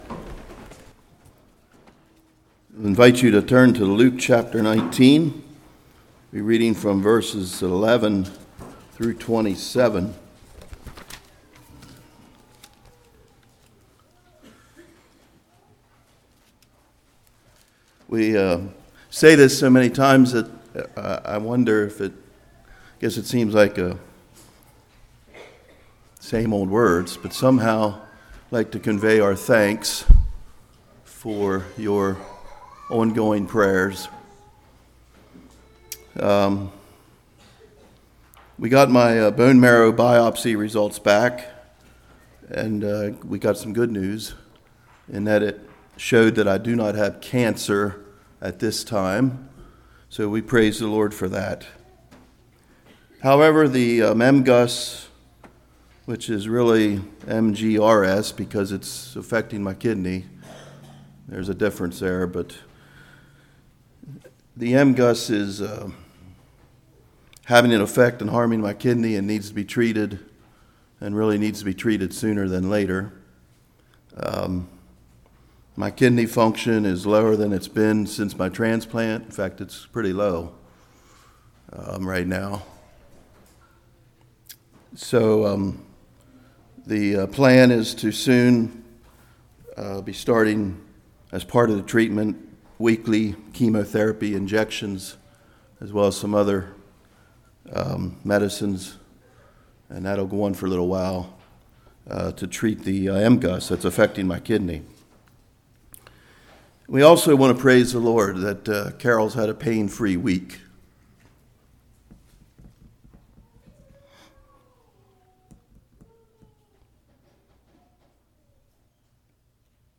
Luke 19:11-27 Service Type: Morning He is coming again.